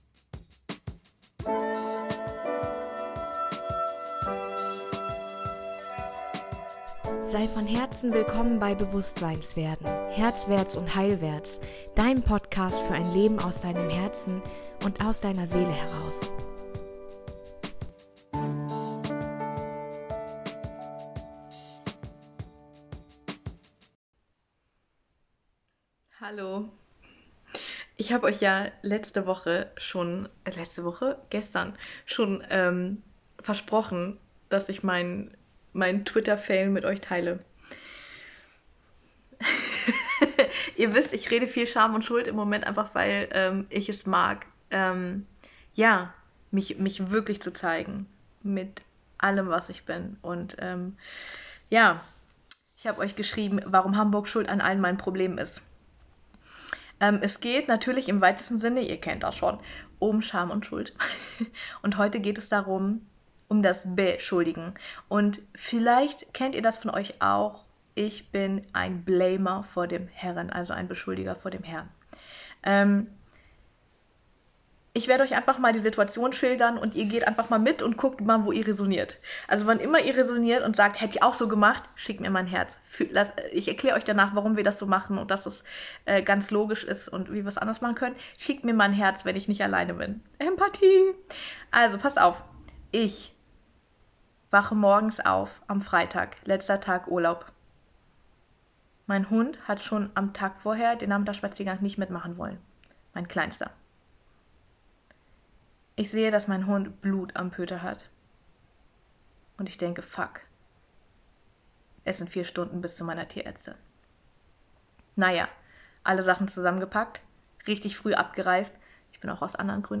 In diesem Facebook Live erzähle ich dir von meiner Heimfahrt aus dem Urlaub und wie wichtig es ist, dass wir verstehen, warum wir beschuldigen und blamen - und dass es immer die Schwächsten trifft, und wir uns deswegen wirklich kennen lernen und verändern dürfen.